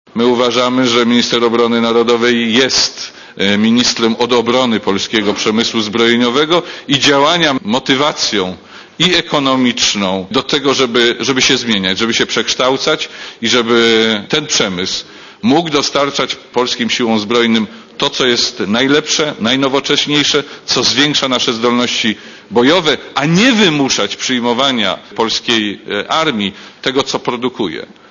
Mówi szef MON, Jerzy Szmajdziński